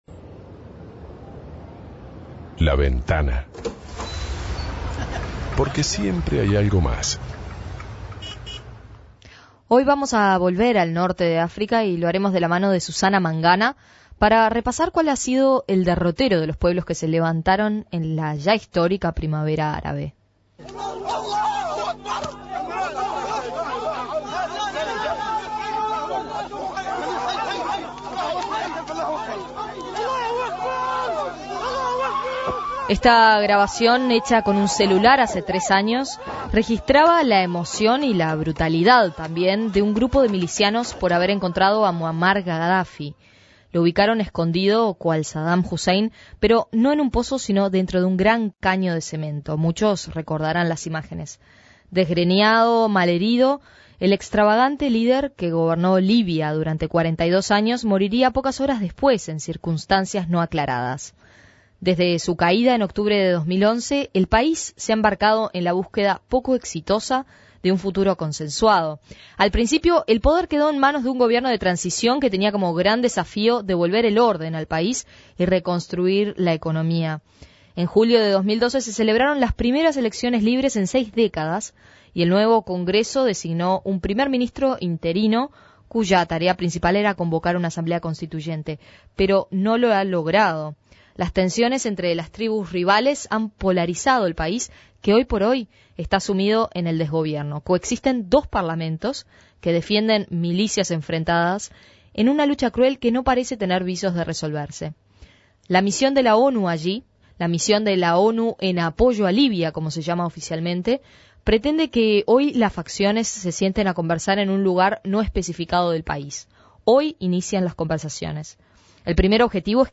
En conversación